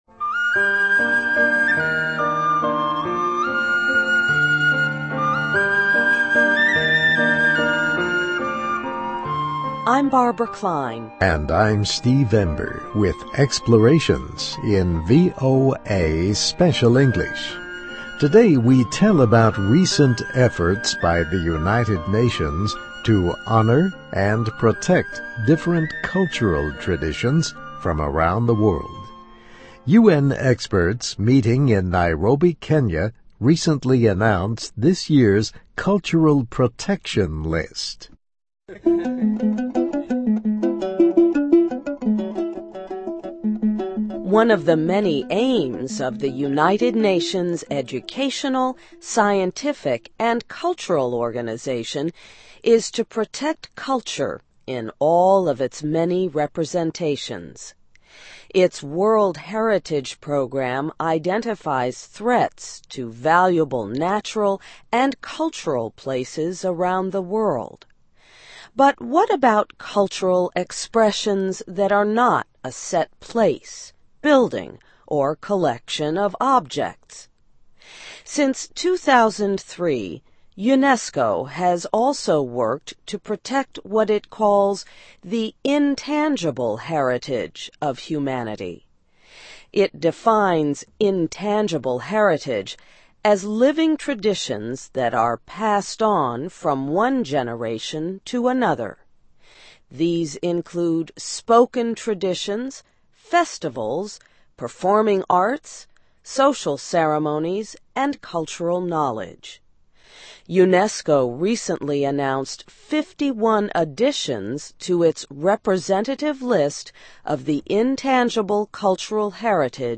(MUSIC)